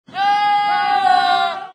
Goat 1 Sound Effect Free Download
Goat 1